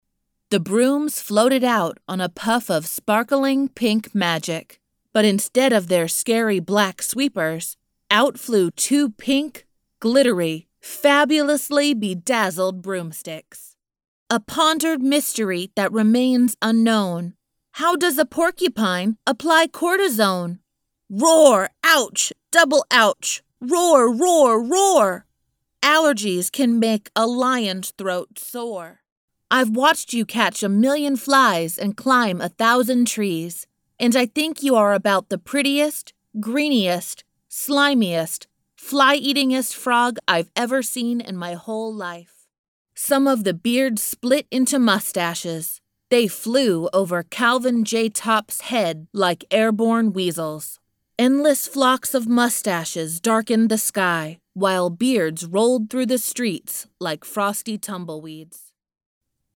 Fun, Sexy, Witty, Unique
Kein Dialekt
Sprechprobe: Sonstiges (Muttersprache):